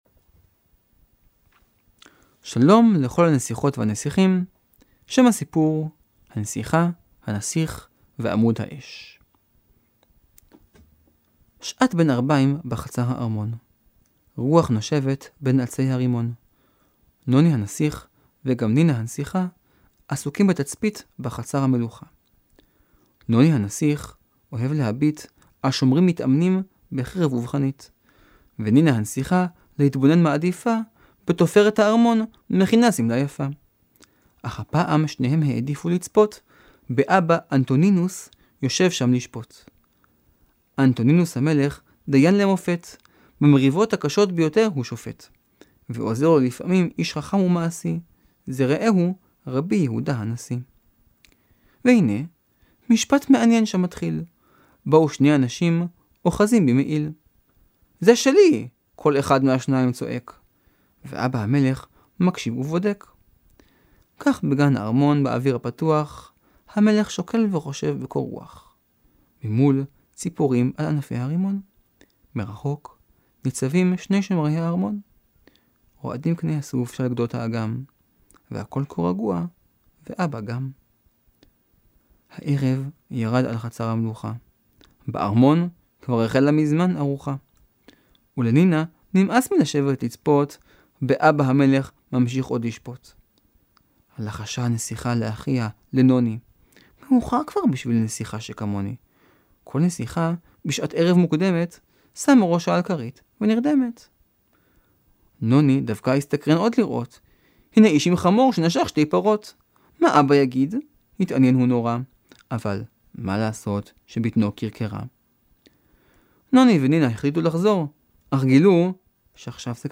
טעימת שמע מתוך ספרי אגדות חז”ל לילדים, והפעם:
אמן אורח: קובי אריאלי